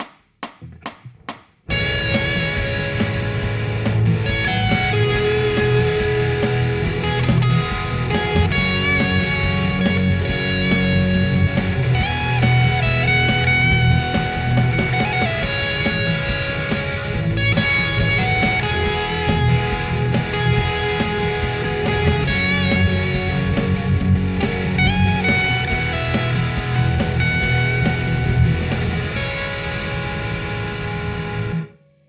Rat -> Amplifier-X -> Covolution Amp/Cab